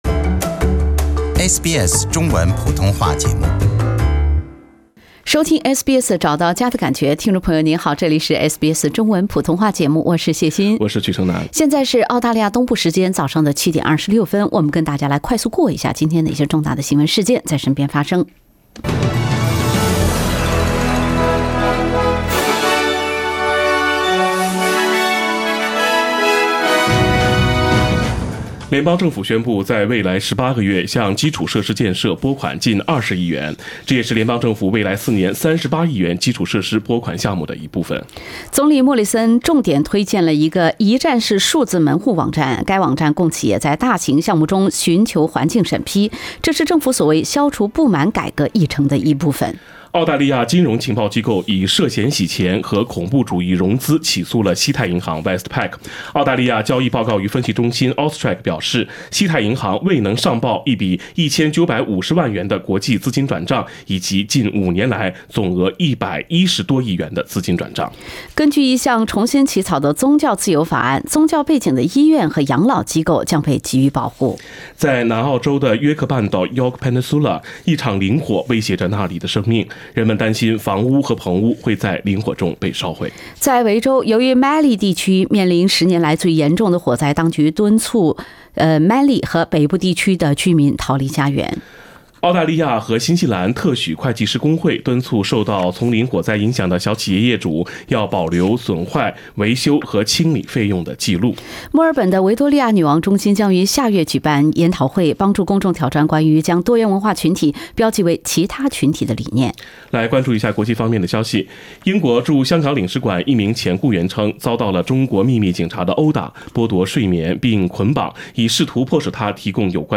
SBS早新闻 （11月21日）